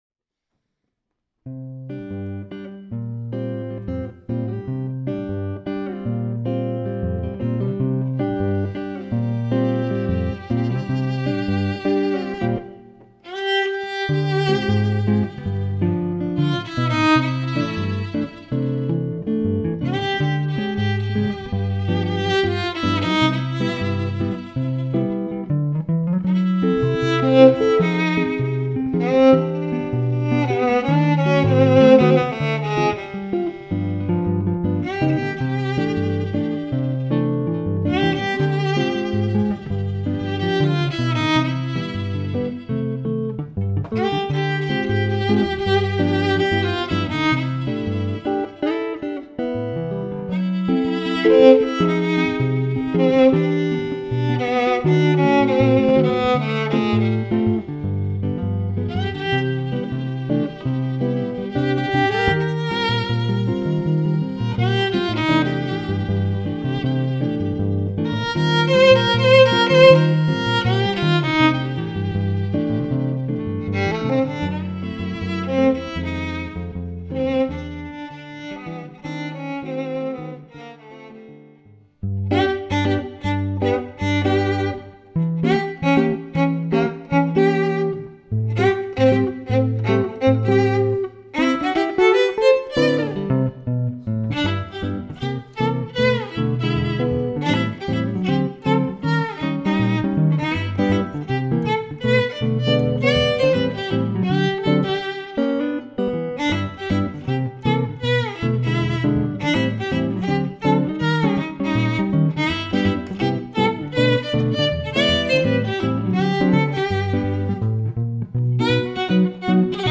This is a sample of music from their current duo project.